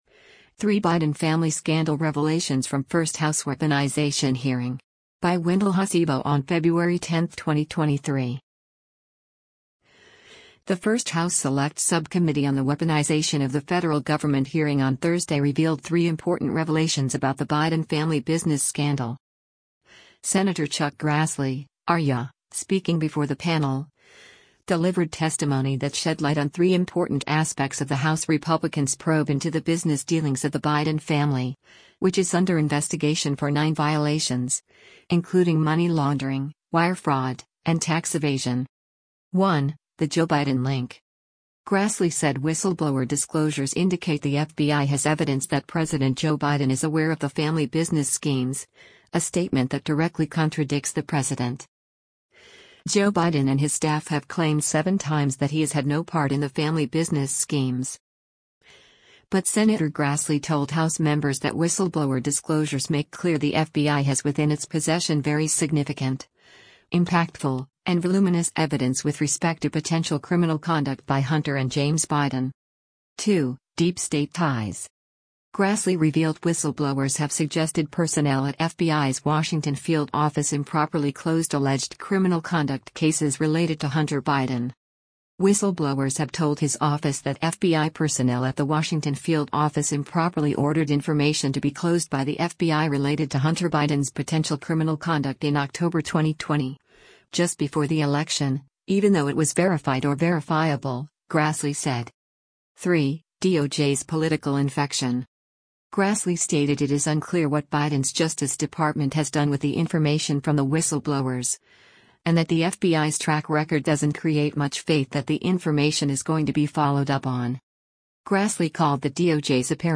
Sen. Chuck Grassley (R-IA), speaking before the panel, delivered testimony that shed light on three important aspects of the House Republicans’ probe into the business dealings of the Biden family, which is under investigation for nine violations, including money laundering, wire fraud, and tax evasion.